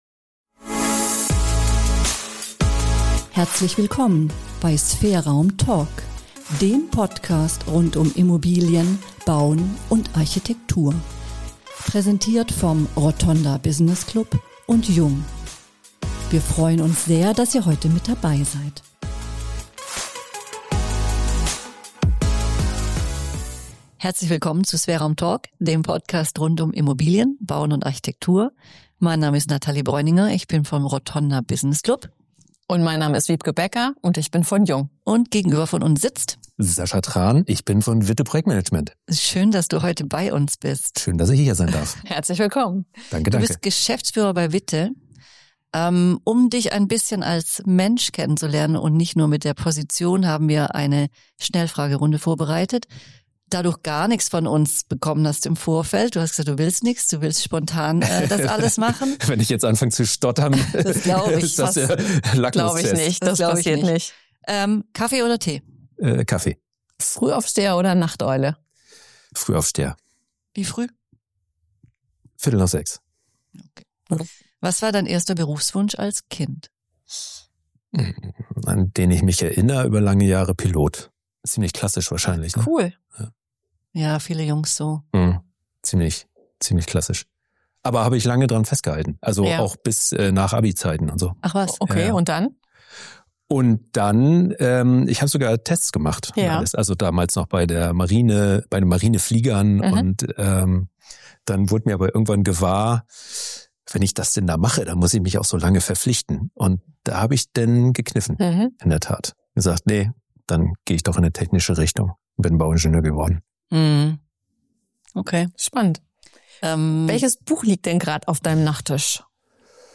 Ein Gespräch über Zusammenarbeit, Haltung und die Kunst, Projekte auf Augenhöhe zum Erfolg zu führen.